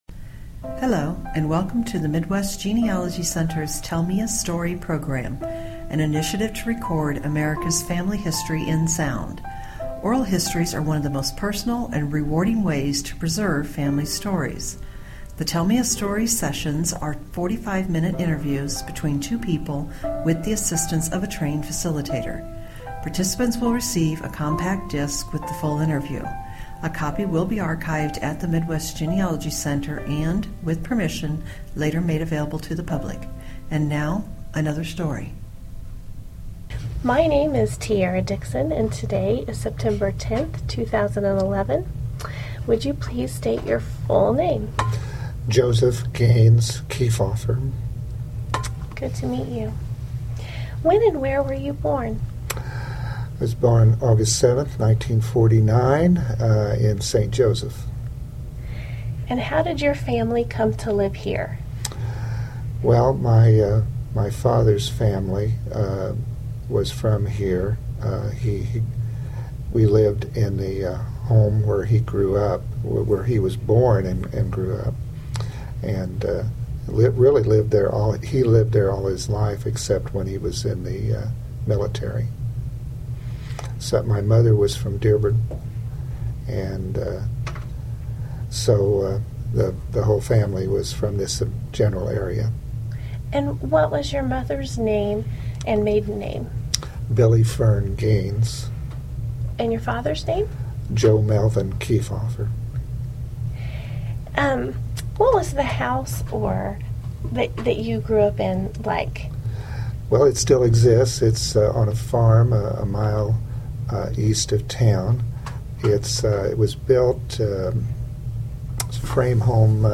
Edgerton, Missouri Pioneer Days 2011
Oral History